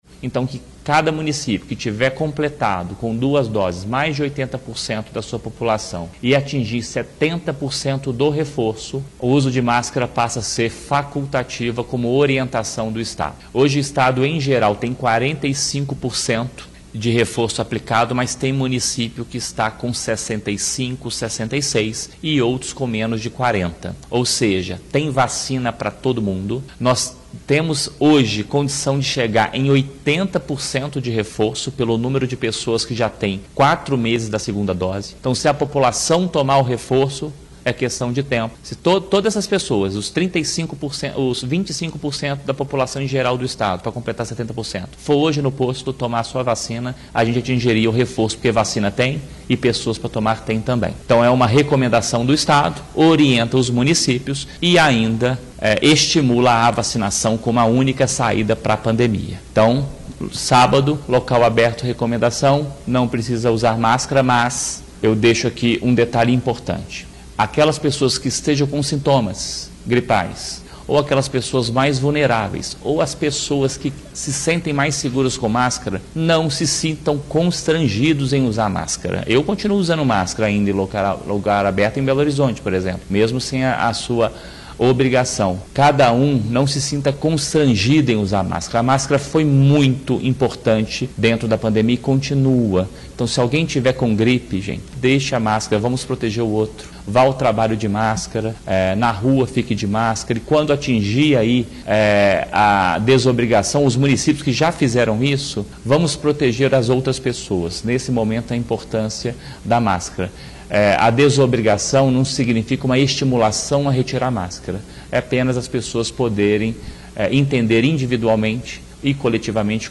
Em coletiva de imprensa realizada nesta quinta-feira(10/03), em Belo Horizonte, o Secretário de Estado de Saúde, Fábio Baccheretti, falou das ações que o Estado realiza em conjunto com as secretarias municipais e da recomendação do fim do uso de máscara em ambientes abertos.
Secretário de Estado de Saúde, Fábio Baccheretti, orienta que os municípios que atingiram 80% da segunda dose da vacina e 70% do reforço, o uso de máscara será facultativo